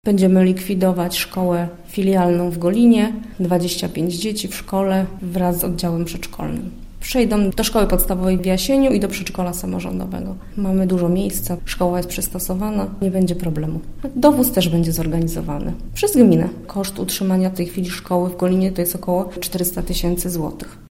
Możemy też zapewnić, że dzieci, które będą od września uczęszczać do szkoły w Jasieniu, na pewno na tym zyskają – tłumaczy Katarzyna Łuczyńska, zastępca burmistrza Jasienia: